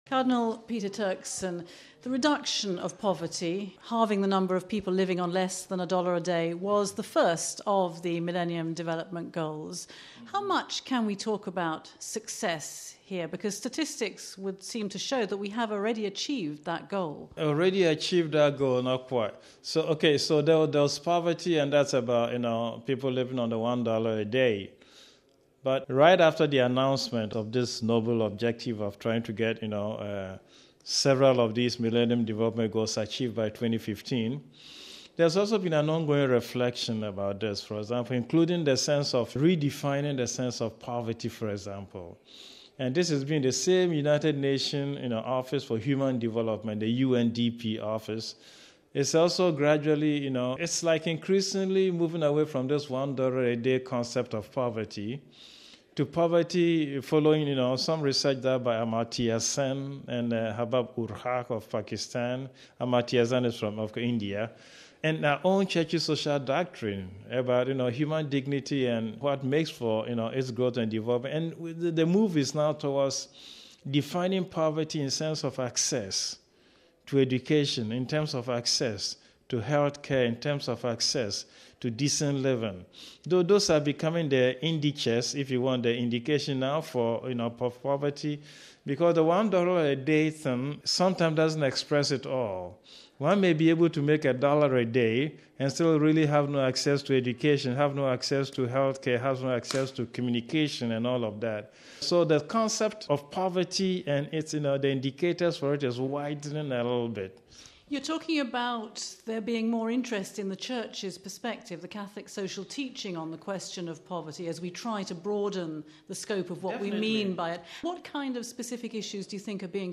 (Vatican Radio)